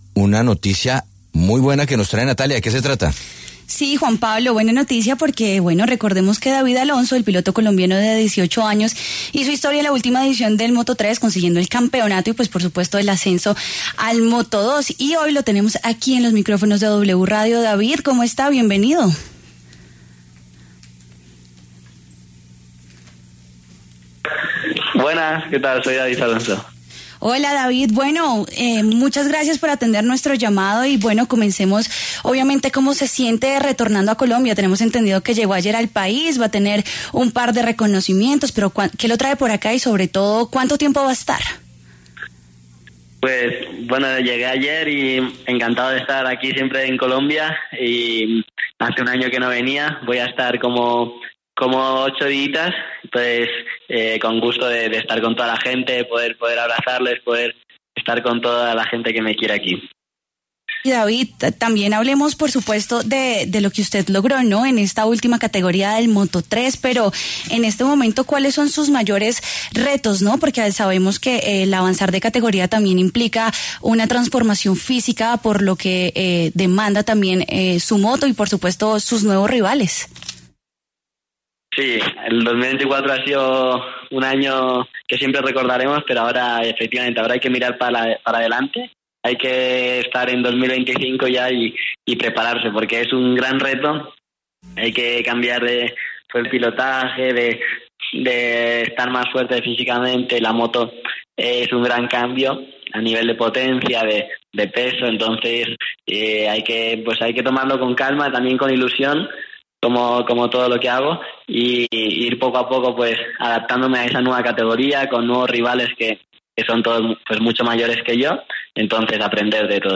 El piloto colombiano de 18 años, pasó por los micrófonos de W Radio y habló sobre las expectativas que tiene este nuevo año con su llegada al Moto 2.